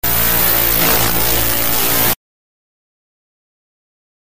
Baldi’s Basics Jumpscare Sound
baldis-basics-baldi-jumpscare-sound-online-audio-converter-2.mp3